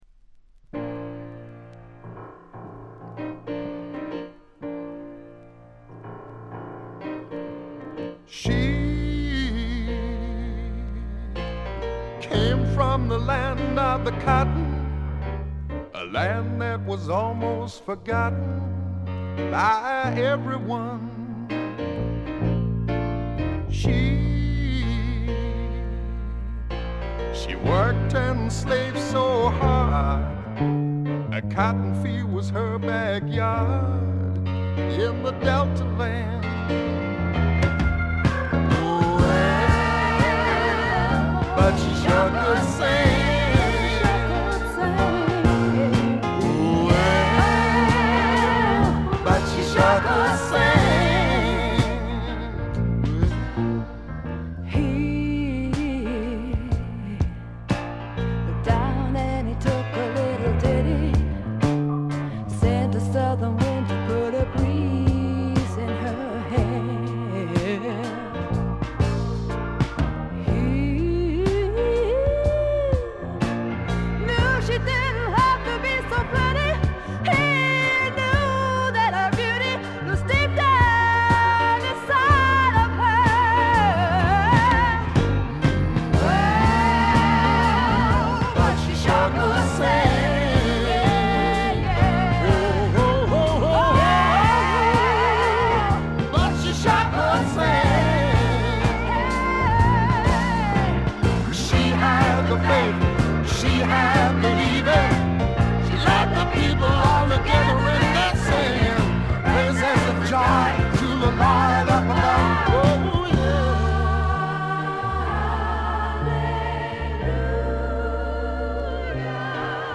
チリプチがちらほら。
試聴曲は現品からの取り込み音源です。